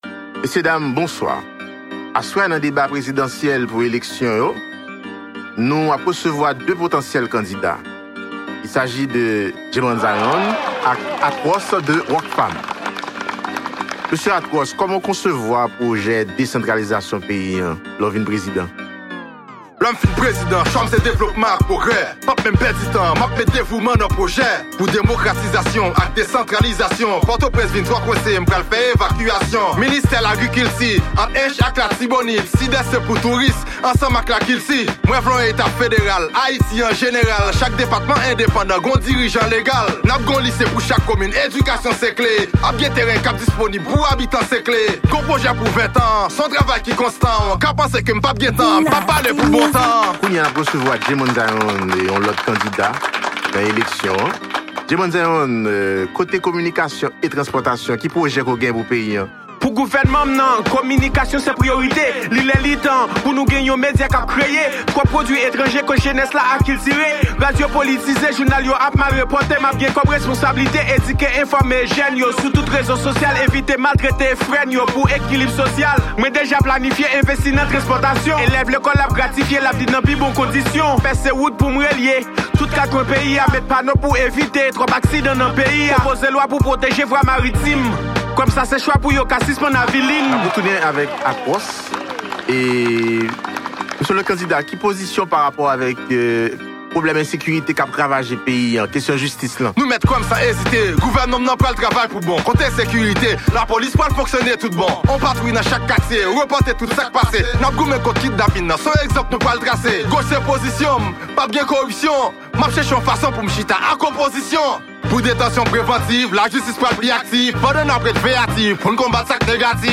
en duo avec le rappeur